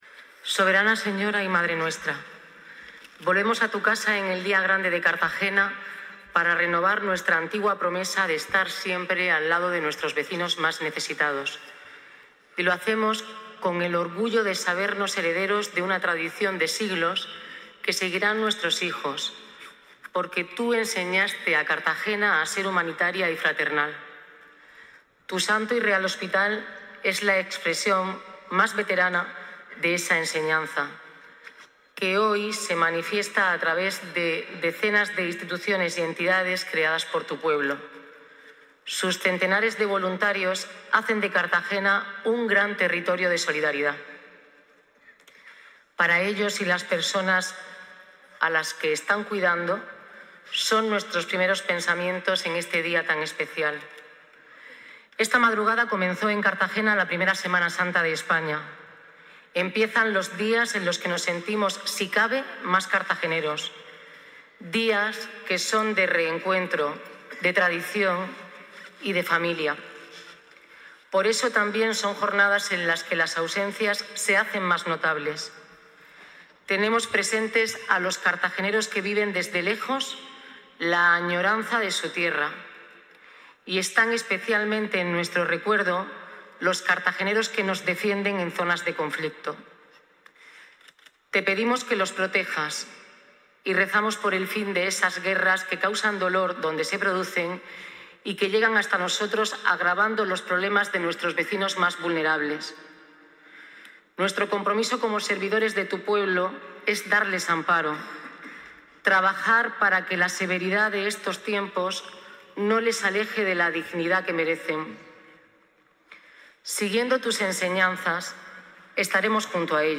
Enlace a Intervención de la alcaldesa en la Onza de Oro
Durante su intervención en la basílica, la alcaldesa puso el foco en las consecuencias económicas derivadas de los conflictos internacionales, apelando a la necesidad de proteger a las familias más vulnerables.